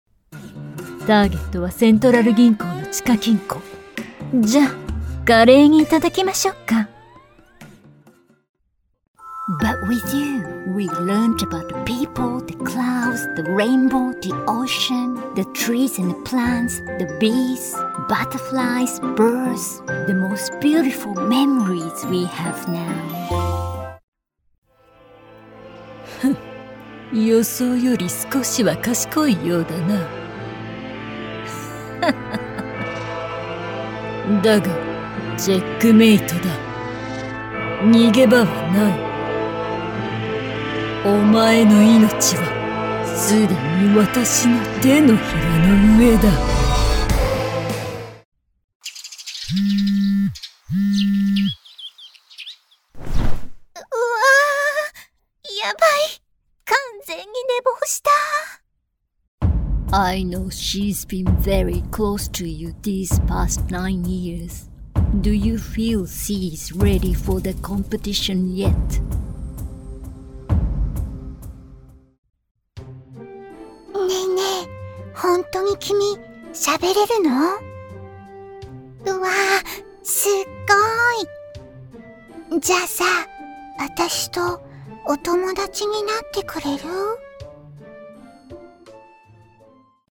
Animation – Japanese & English | Expressive & Authentic
Neutral international or Japanese-accented English
• Neumann TLM 103 condenser microphone